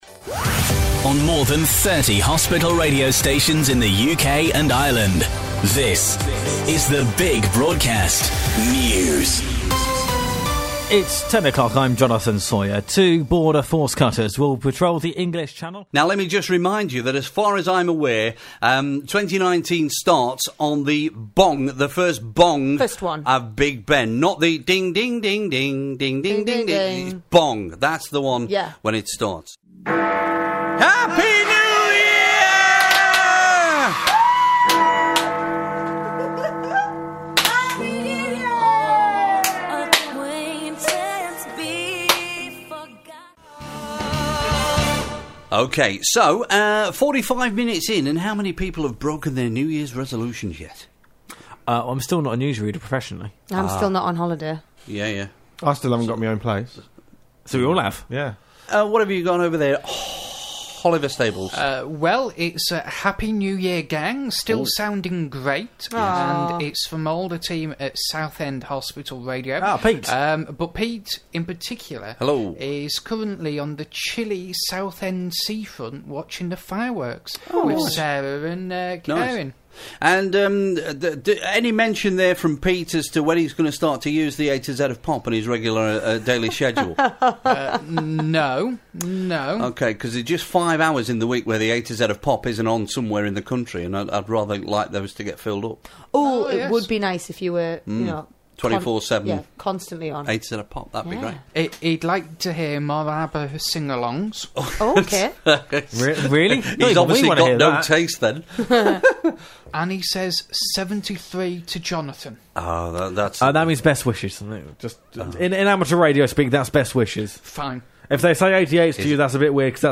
Over 35 of the UK’s network of Hospital Radio stations linked together between the 30th of December 2018 and the 1st of January 2019 for “The Big Broadcast 2018” – a 42-hour marathon broadcast.